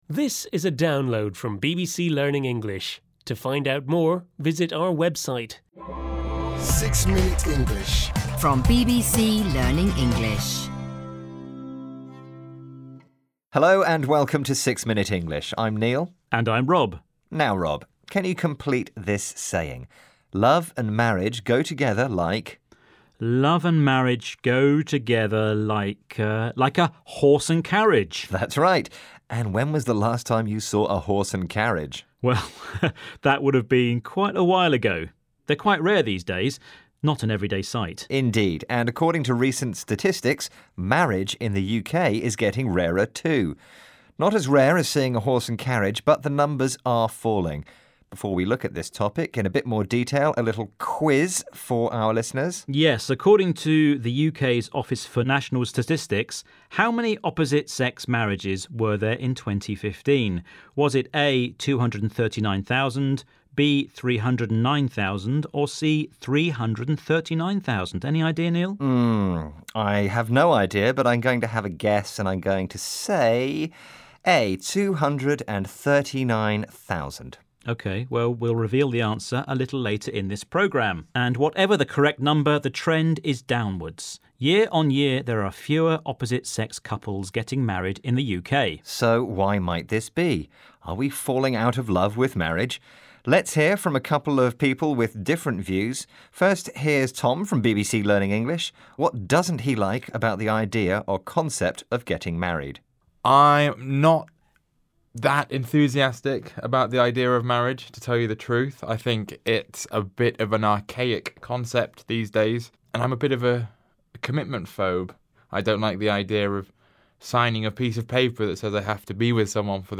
این پادکست ها لهجه بریتیش دارند و برای تقویت لیسنینگ، یادگیری لغات جدید، یادگیری زبان مورد نیاز برای صحبت درباره موضوعات مختلف، و بهبود مهارت اسپیکینگ بسیار مفیدند.